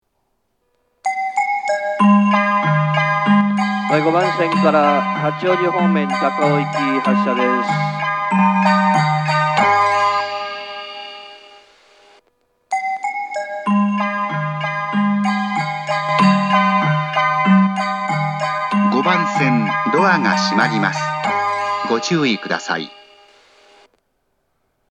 発車メロディー
1.7コーラス（2コーラス）です。